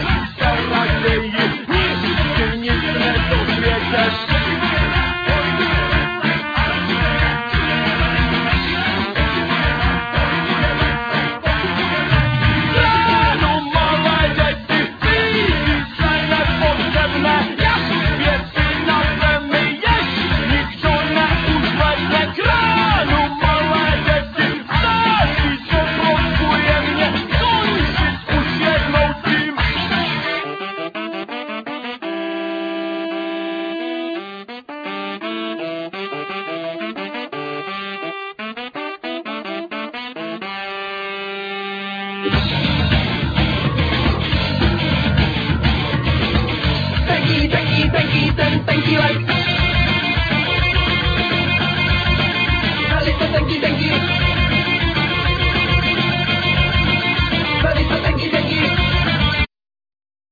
Guitar,Keyboards,Tambarine,Vocal
Tenor,sazophone,Vocal
Drums
Trumpet
Accordion
Tuba